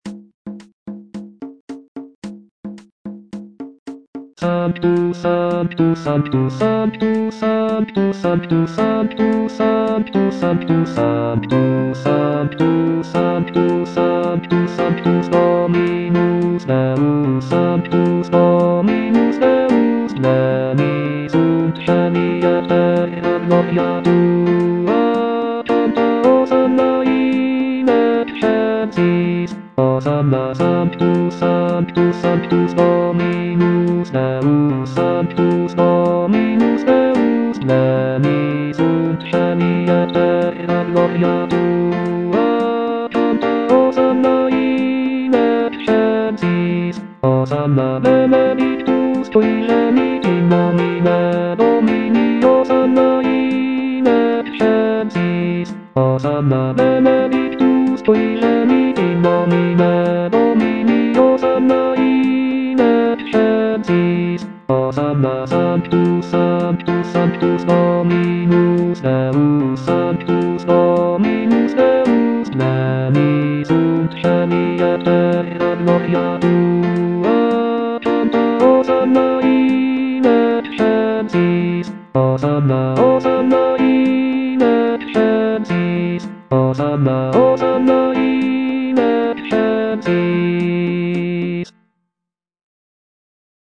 Bass (Voice with metronome) Ads stop